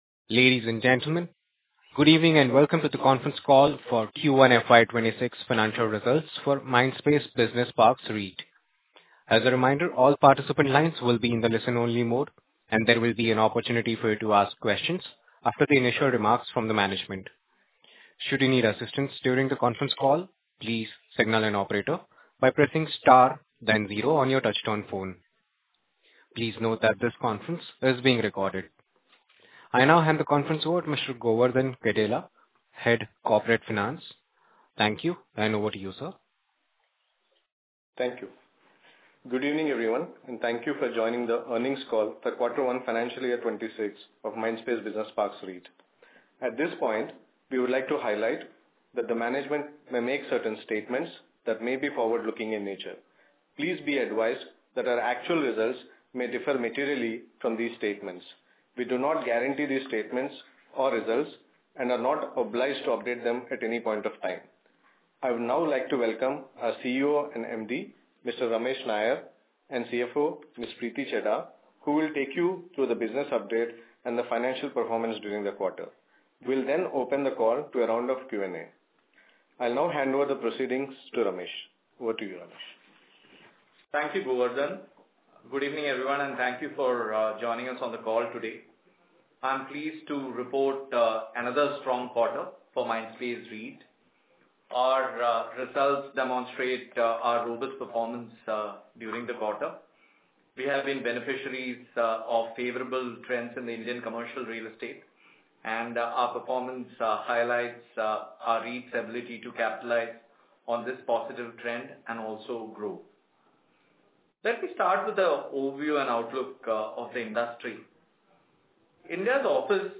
Earnings Conference Call for Financial Results for the Quarter ended March 31, 2024